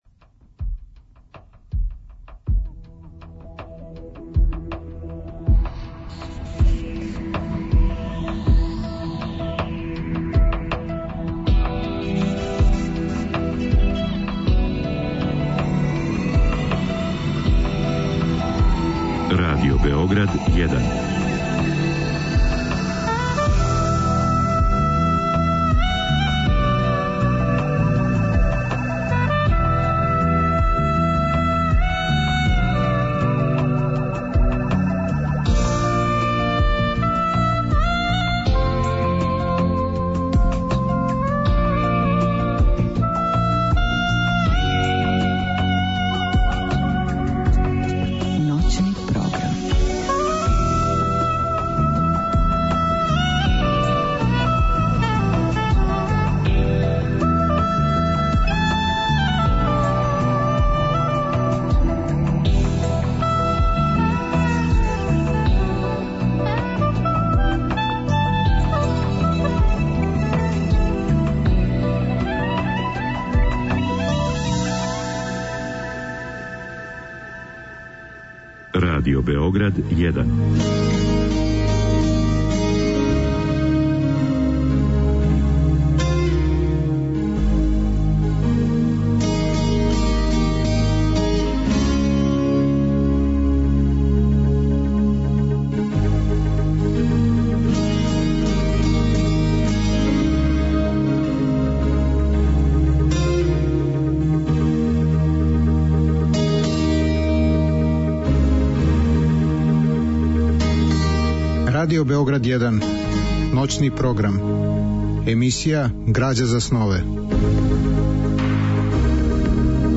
У другом делу емисије, од два до четири часa ујутро, слушаћемо делове радио-драме Сећање на детињство Стевана Раичковића, која је реализована 1983. године у продукцији Драмског програма Радио Београда.